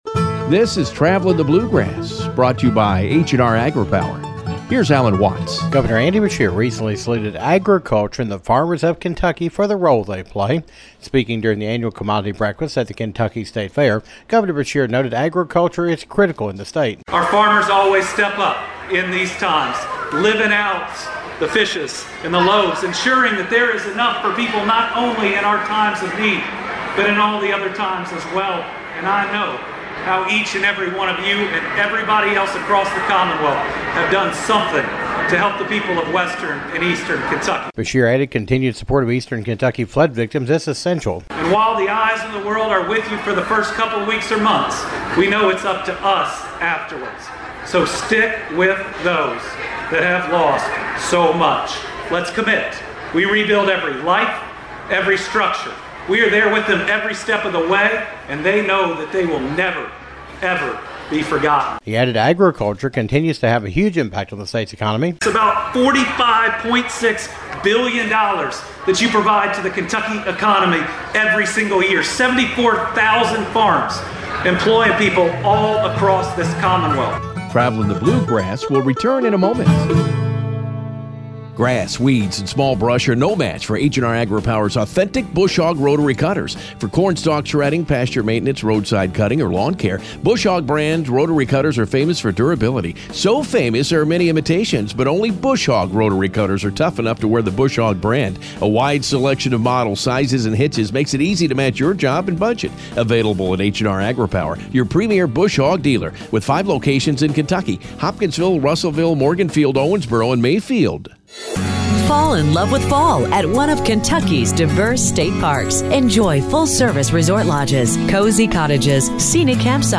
Governor Andy Beshear recently saluted agriculture during the Kentucky State Fair Commodity Breakfast.  Beshear thanked farmers for their resolve and giving spirit and noted the continued growth in the agriculture industry.